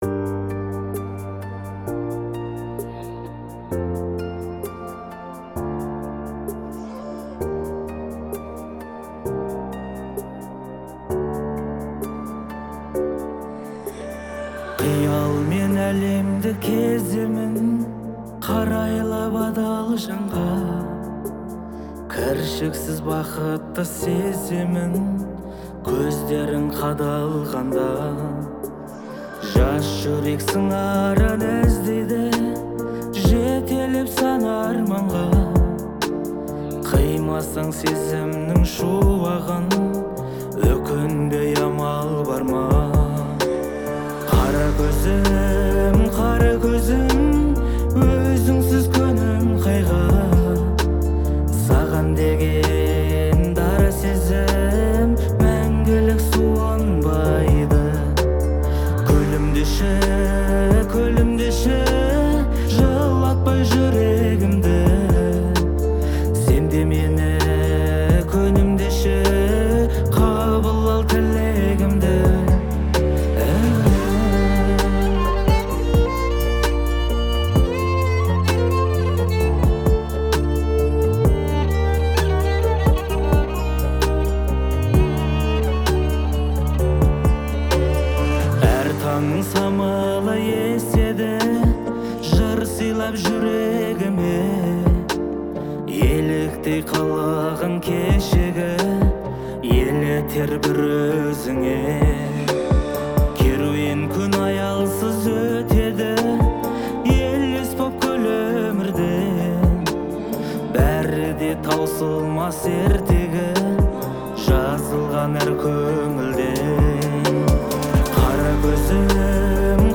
это известная казахская песня в жанре народной музыки